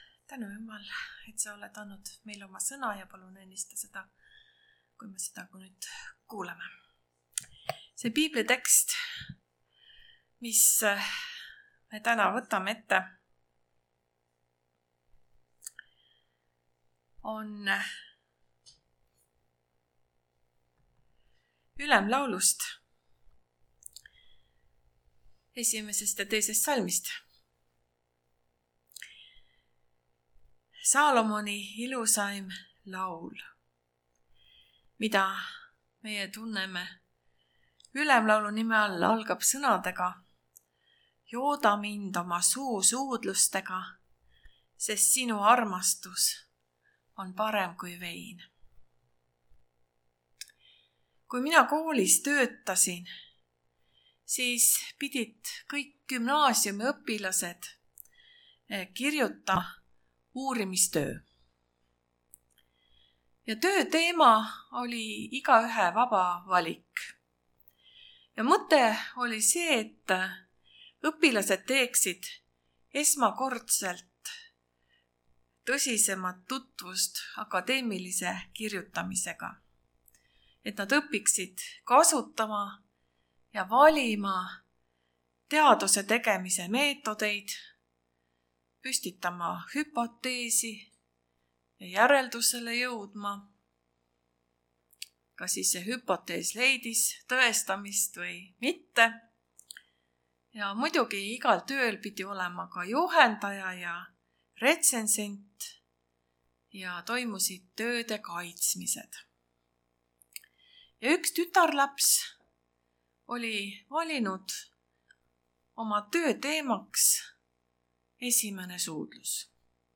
Janu armastuse järele (Võrus)
Jutlused